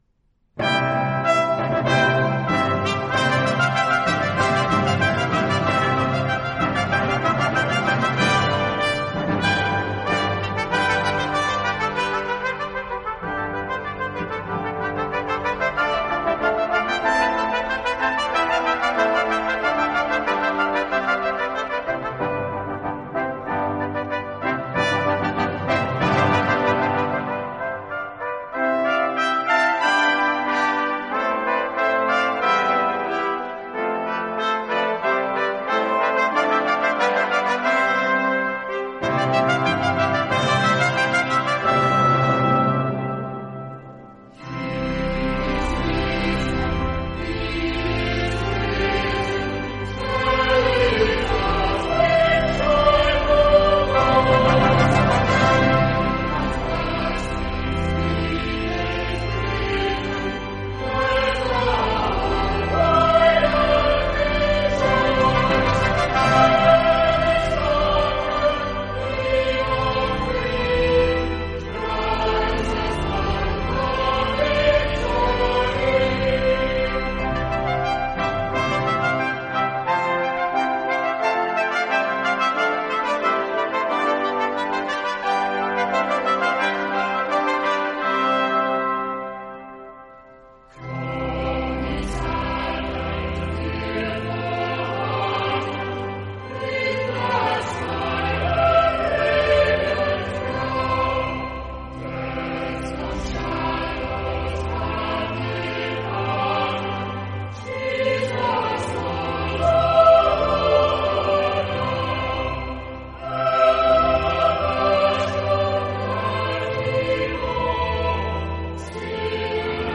Performed by the Choir of Saint Thomas Church New York City and the Saint Thomas Brass conducted by John Scott.